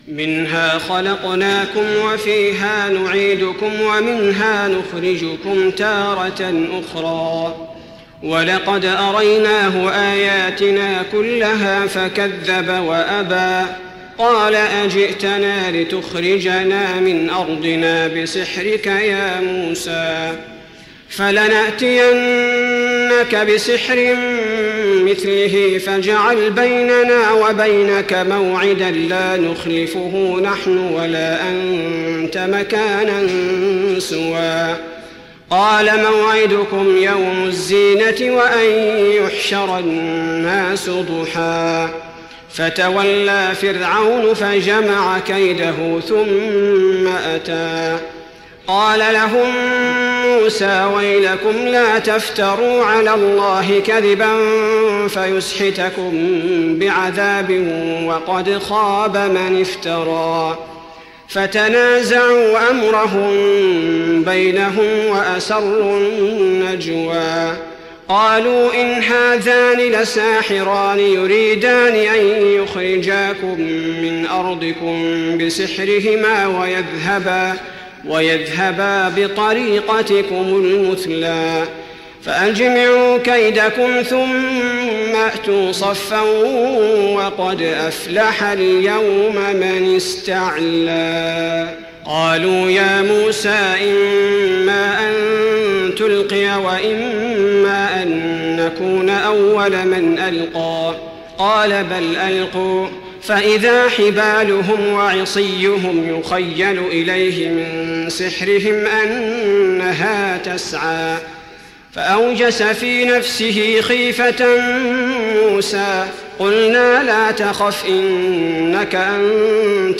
تراويح رمضان 1415هـ من سورتي طه (55-135) و الأنبياء (1-40) Taraweeh Ramadan 1415H from Surah Taa-Haa and Al-Anbiyaa > تراويح الحرم النبوي عام 1415 🕌 > التراويح - تلاوات الحرمين